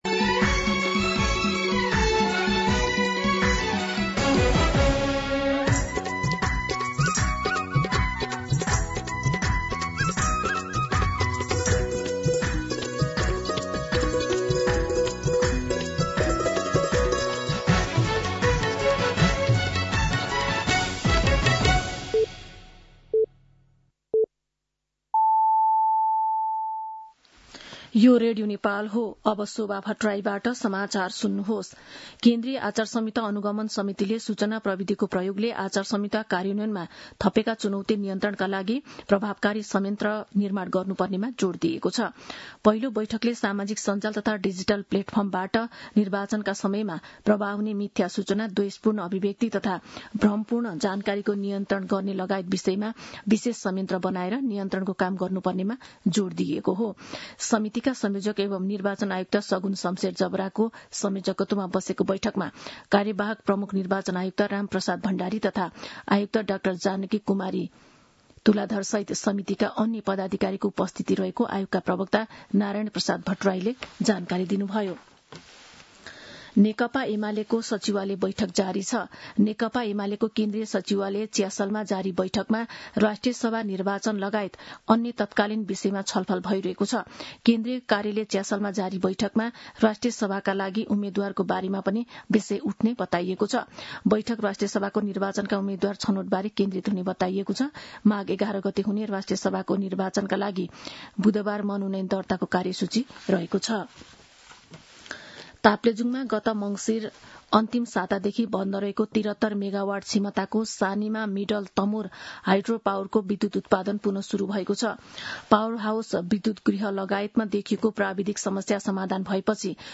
An online outlet of Nepal's national radio broadcaster
मध्यान्ह १२ बजेको नेपाली समाचार : २२ पुष , २०८२